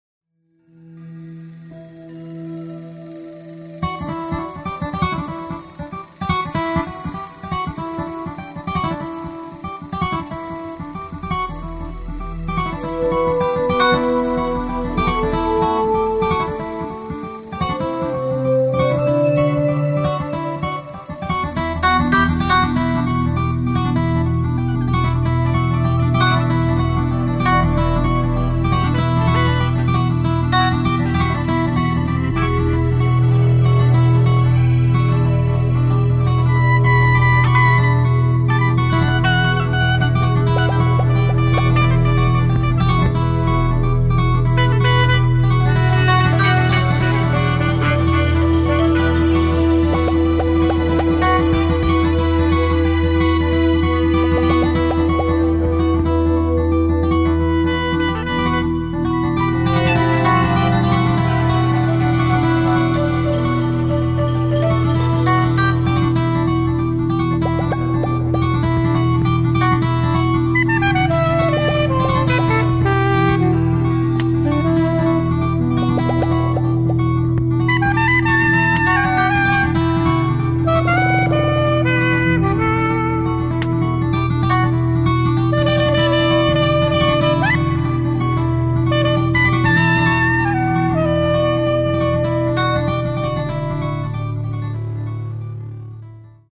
unterbewußt aus der errinnerung gespielt